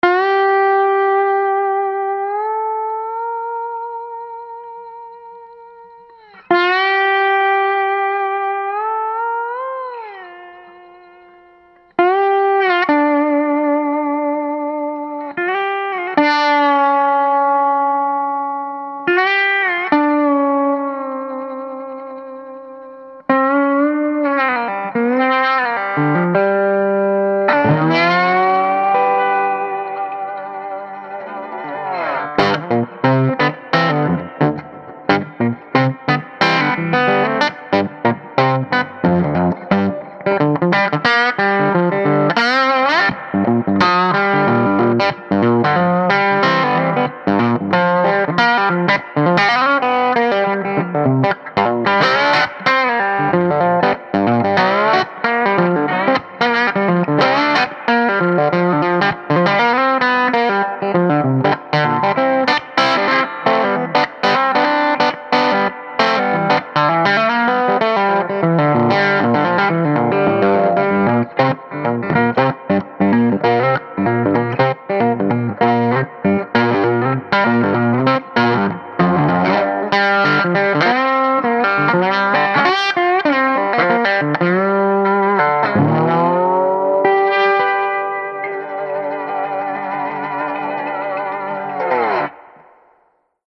5W Class A - Single-Ended - 6V6 or 6L6 - Tube Rectified ~ ALL NEW Triode REVERB ~ 14lbs
DragonFlys • Tele • Medium Gain   1:33